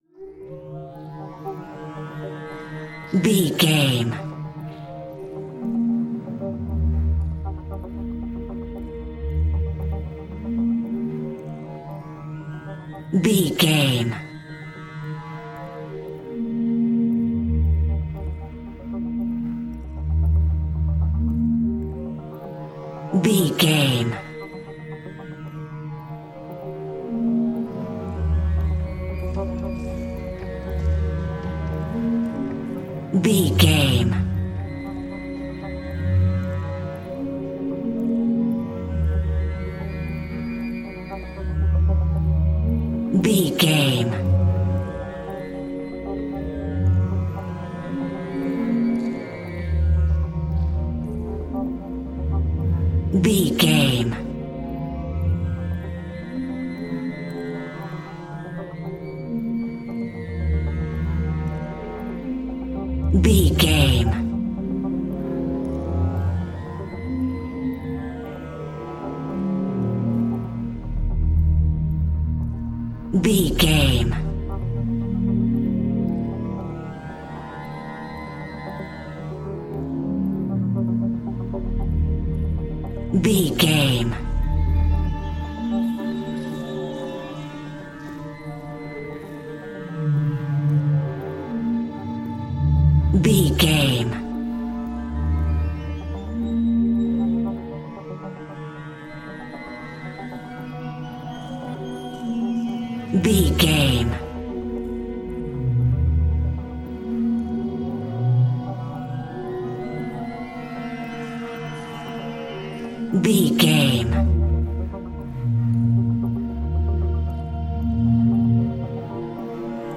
Atonal
tension
ominous
dark
eerie
synthesiser
Horror Ambience
Synth Pads
Synth Ambience